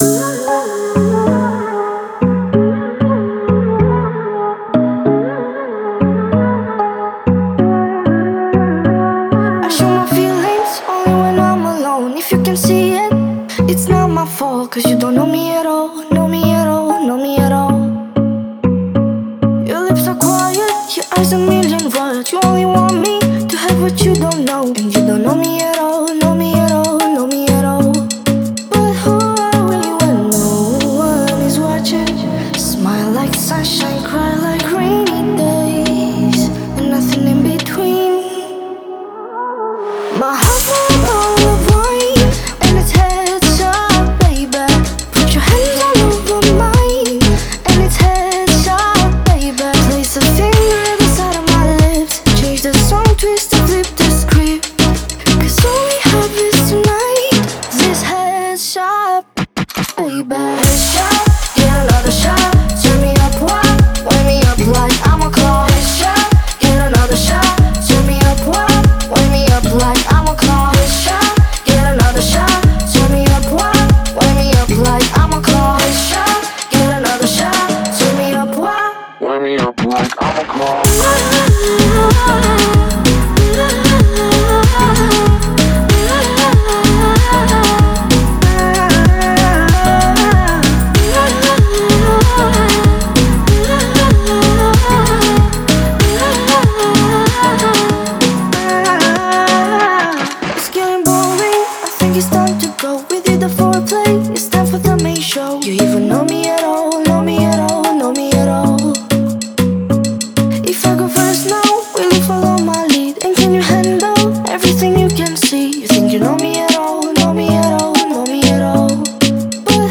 это трек в жанре R&B